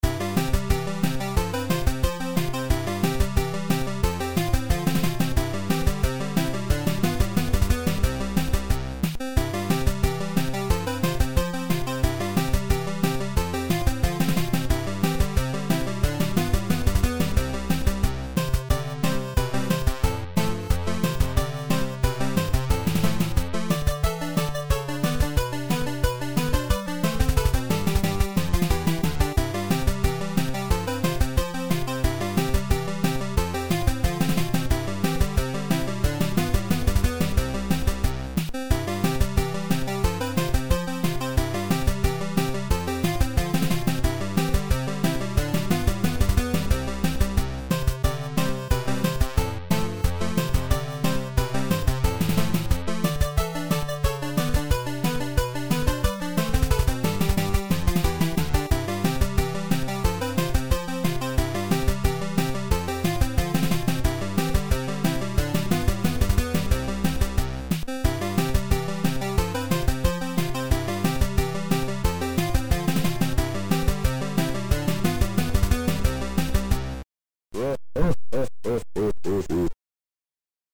8-bit chiptunes version